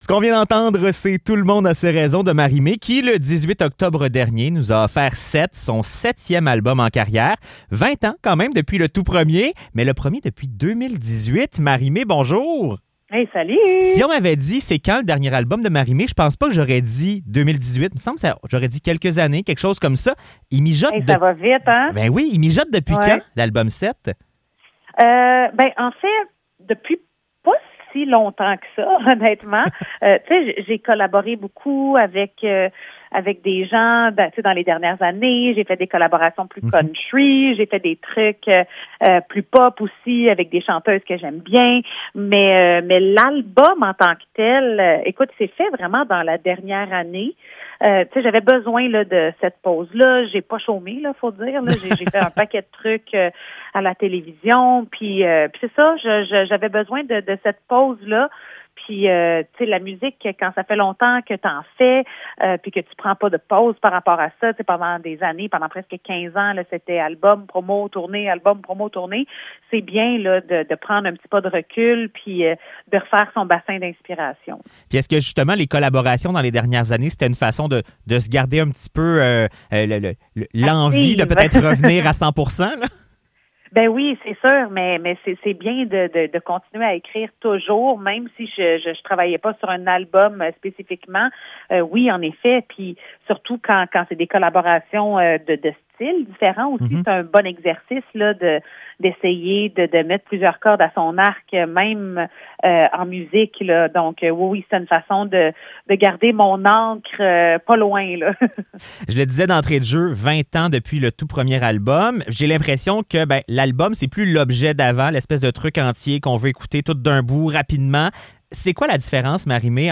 Entrevue avec Marie-Mai